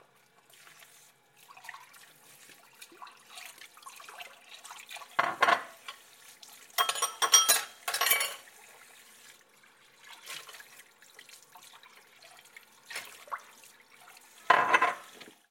Dishes